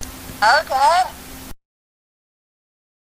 contribs)Ness' sound clip when selected with a Wii Remote.